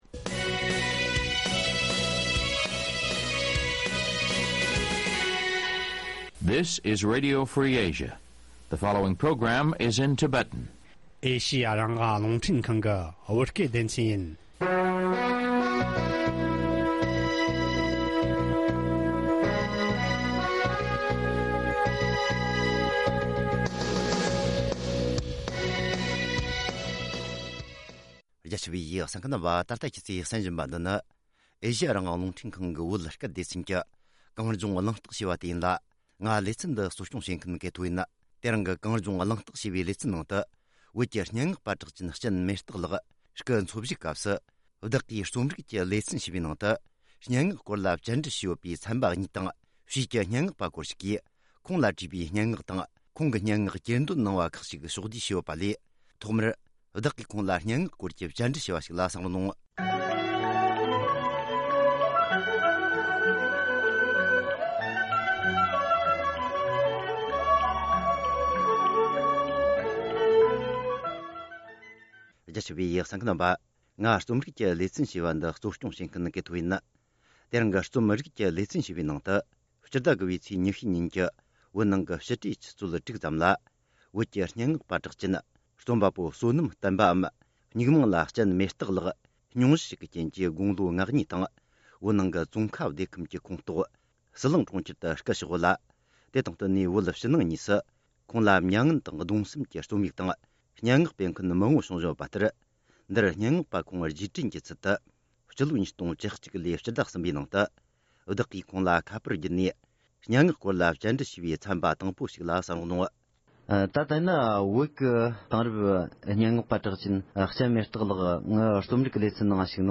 སྙན་ངག་སྐོར་གྱི་བཅར་འདྲི་
བྱེས་ཀྱི་སྙན་ངག་པ་དག་གིས་ཁོང་དང་འབྲེལ་བའི་སྙན་ངག་གྱེར་འདོན་བྱས་པ་ཁག་གཅིག་